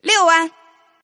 Index of /client/common_mahjong_tianjin/mahjonghntj/update/1308/res/sfx/tianjin/woman/